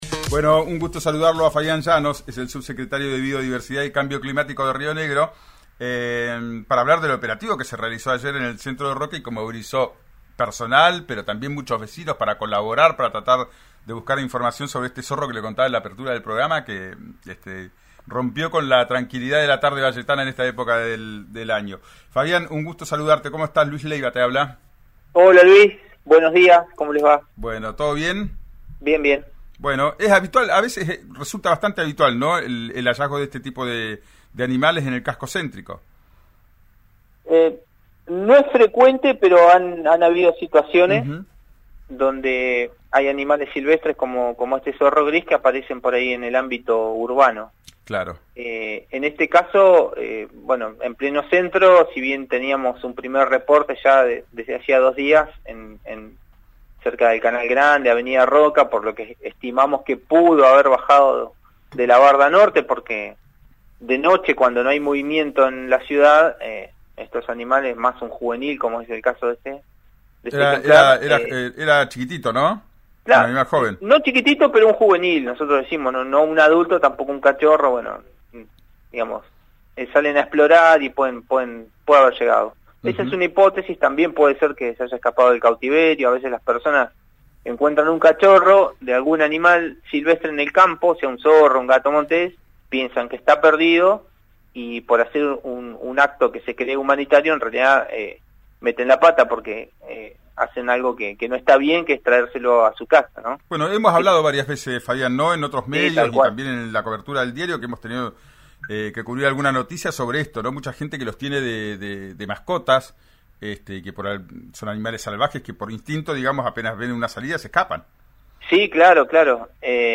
Escuchá a Fabián Llanos, subsecretario de Biodiversidad y Cambio Climático de Río Negro, en «Ya es tiempo» por RÍO NEGRO RADIO: